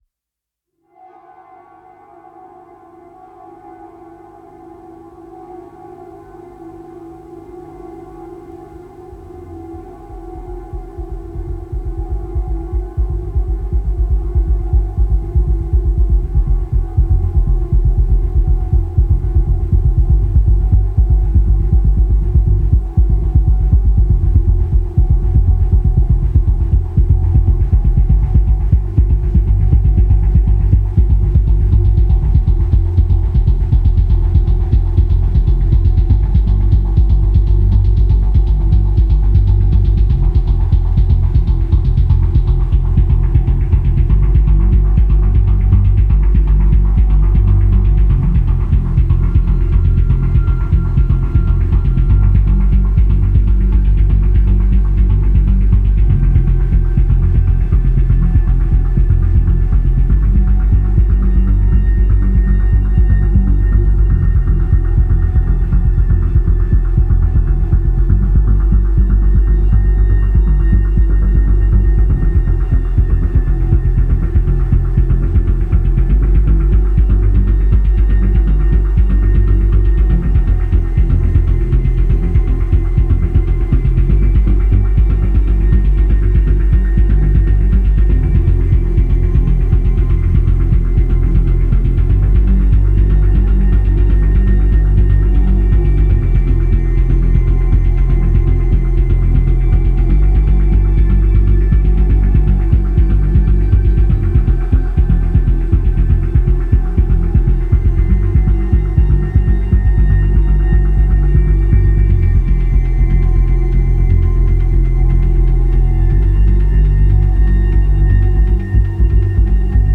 Pulsating synth arpeggios and hazy textures.